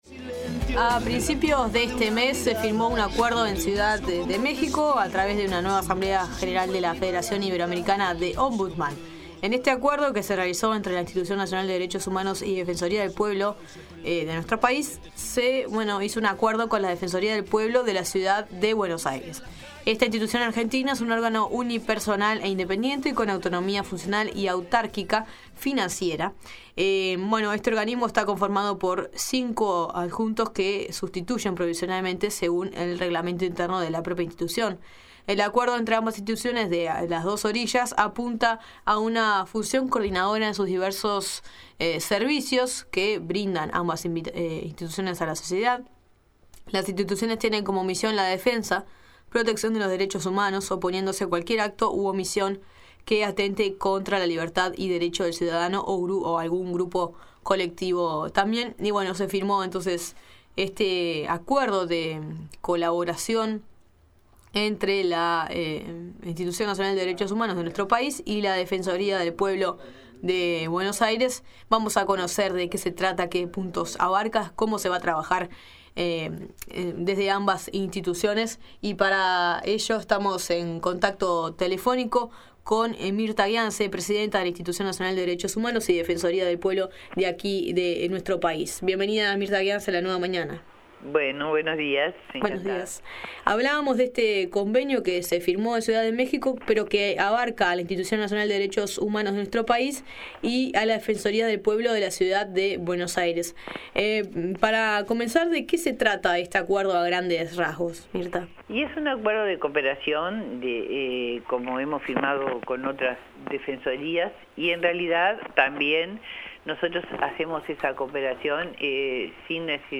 Para conocer sobre el alcance de este tratado de cooperación binacional, en La Nueva Mañana entrevistamos a Mirtha Guianze, Presidenta de la Institución Nacional de Derechos Humanos y Defensoría del Pueblo.